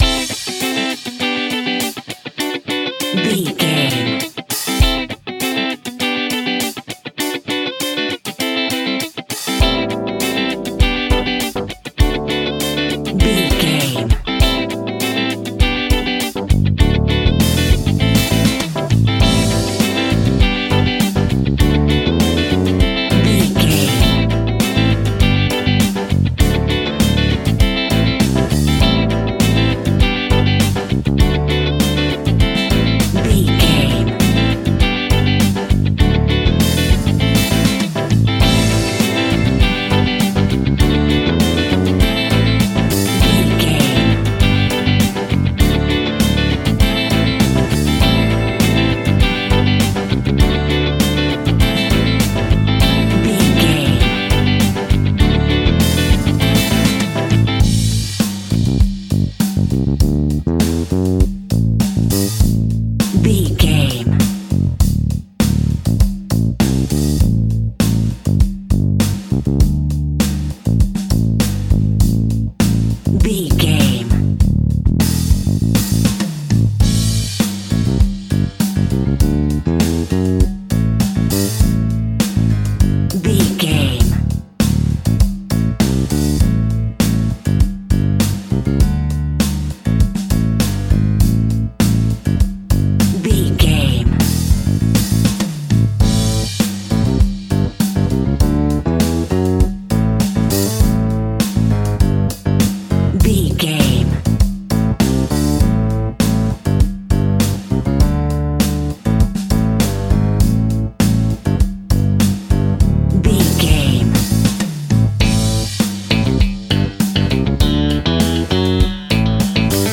Uplifting
Aeolian/Minor
pop rock
indie pop
fun
energetic
acoustic guitars
drums
bass guitar
electric guitar
piano
organ